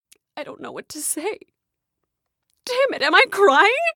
don't-know-what-to-say-crying